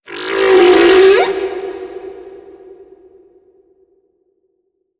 khloCritter_Male31-Verb.wav